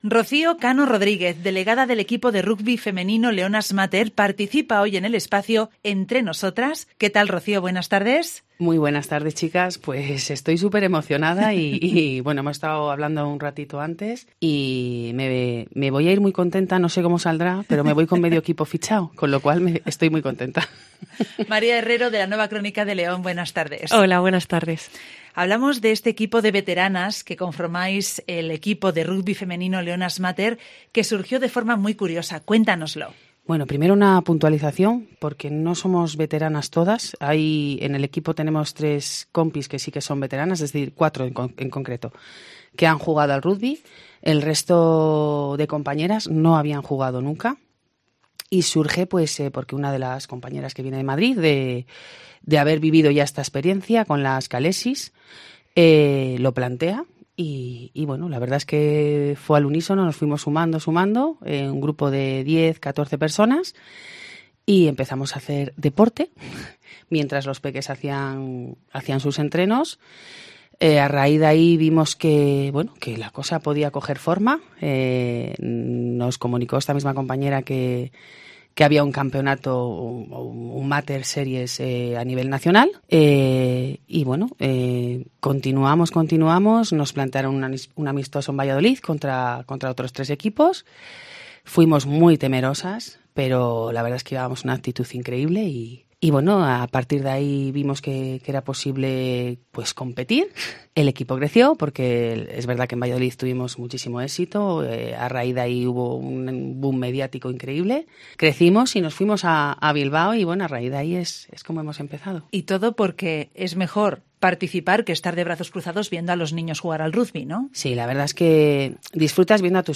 participa en el espacio “Entre Nosotras” en Mediodía en COPE León.